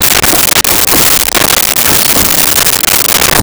Door Antique Open Close 01
Door Antique Open Close 01.wav